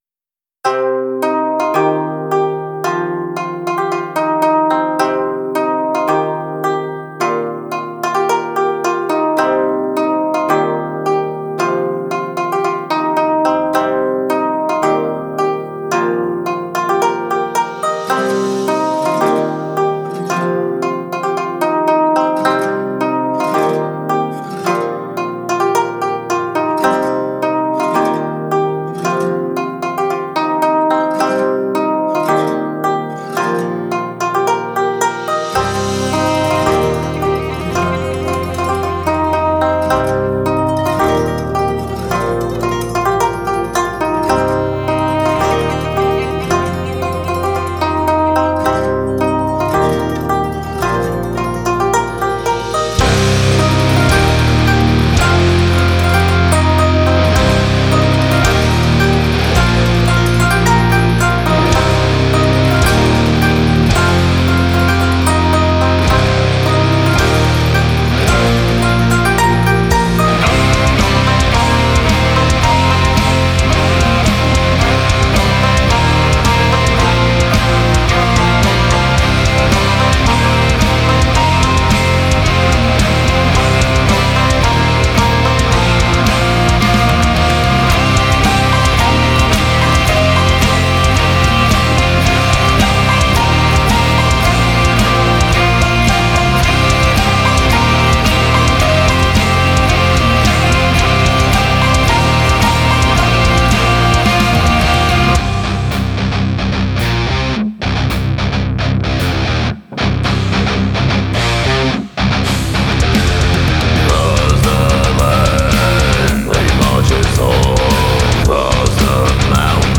Фолк Рок